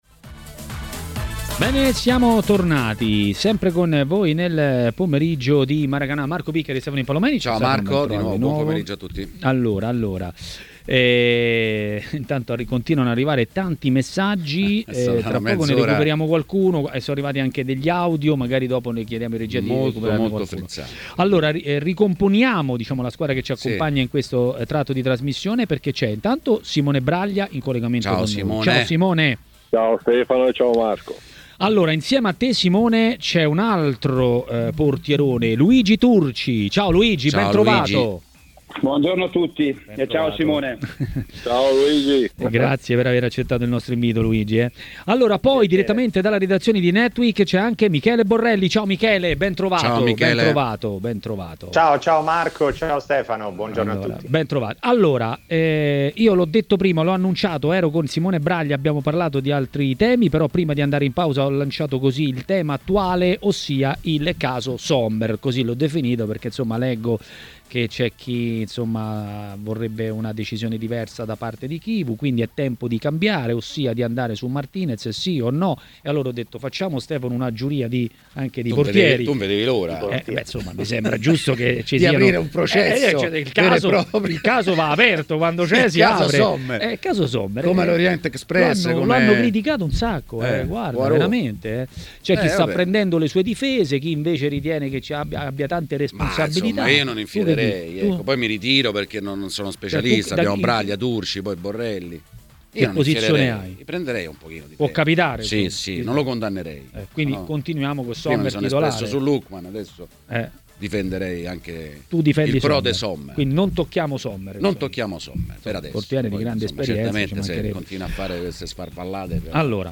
A commentare le notizie di giornata a Maracanà, trasmissione di TMW Radio